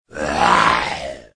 start zombie.mp3